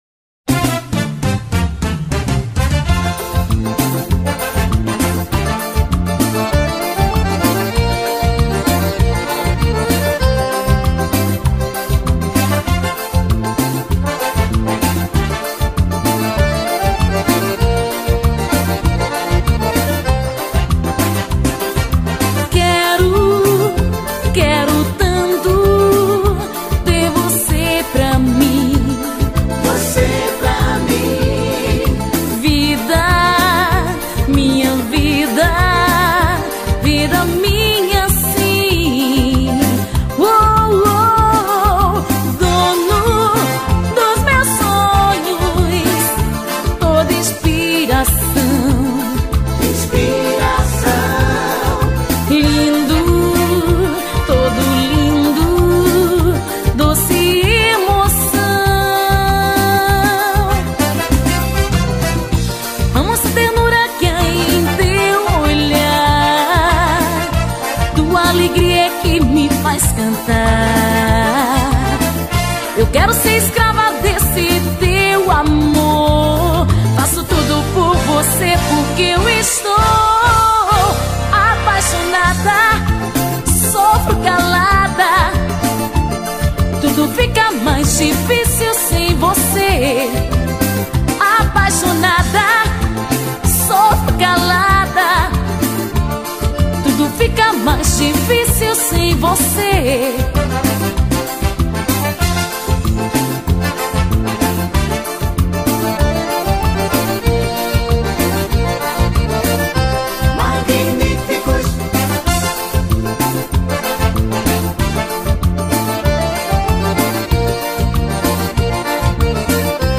UM FORROSINHO DS ANTIGAS
forró